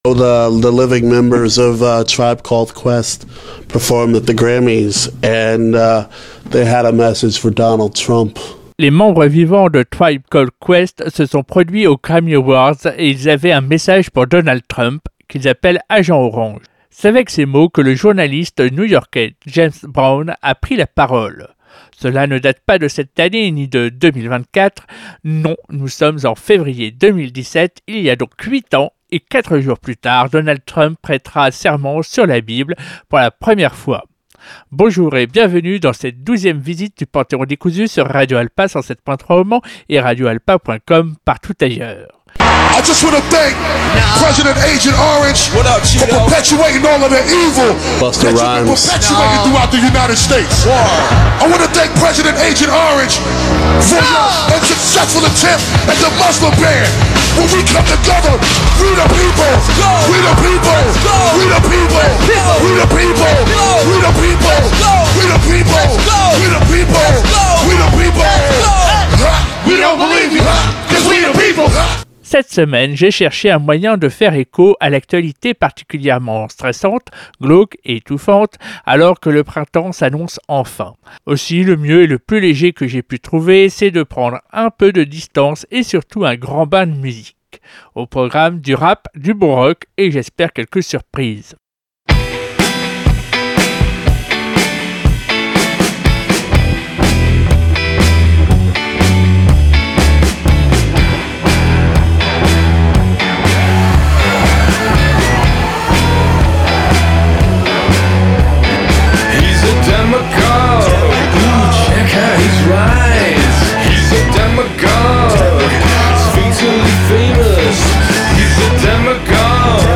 Alors que le monde entier s’interroge sur le rapprochement soudain de Donald Trump et Vladimir Poutine, ce revirement total en si peu de temps et sans aucun signe avant-coureur ou presque, petit panorama de prises de positions contre ces autocrates – pour ne pas dire davantage. Ce qui vous attend : du rap new-yorkais, du bon rock, y compris en russe… et, j’espère, quelques surprises !